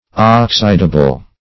Search Result for " oxidable" : The Collaborative International Dictionary of English v.0.48: Oxidable \Ox"i*da*ble\, a. [Cf. F. oxydable.]